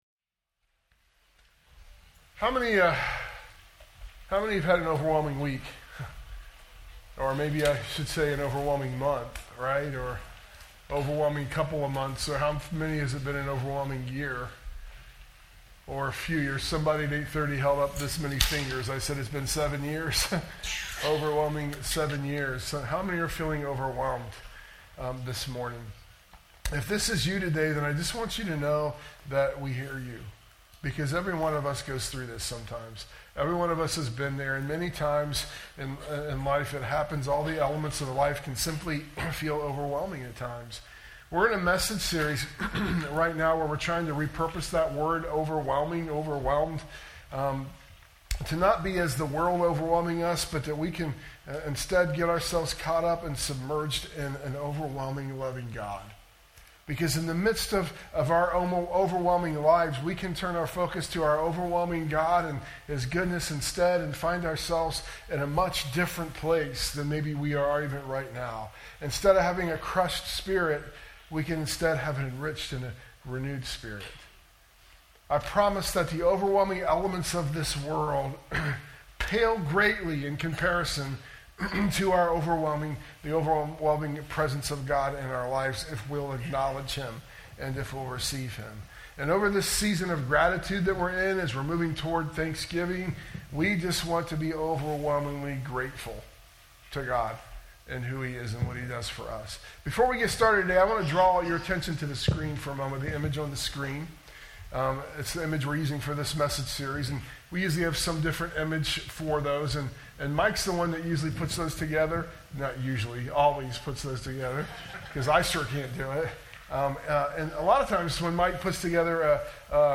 sermon_audio_mixdown_11_9_25.mp3